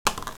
SingleRoll_1.wav